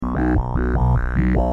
Oberheim - Matrix 1000 10